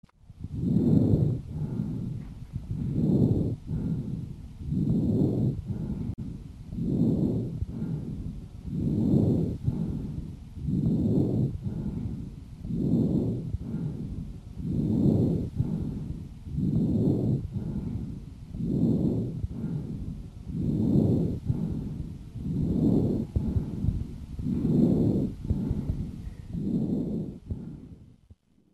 Хрипы влажные мелкопузырчатые после покашливания